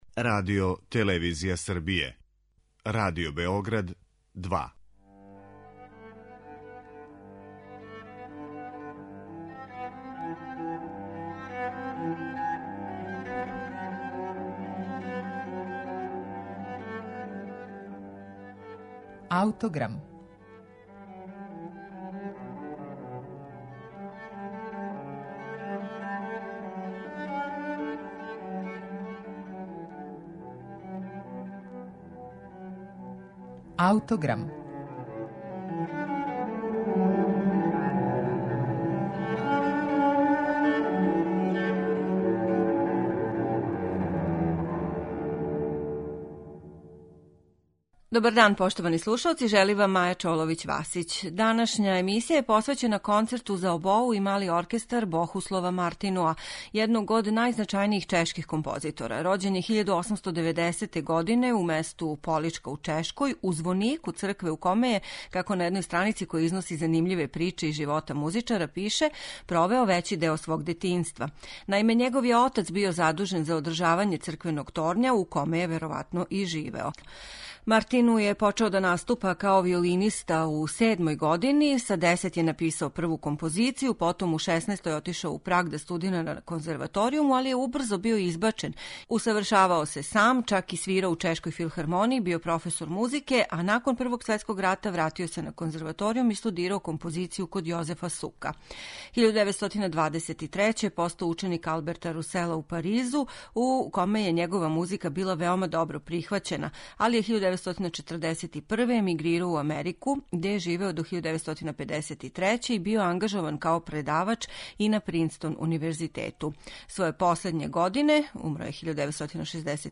концерту за обоу и мали оркестар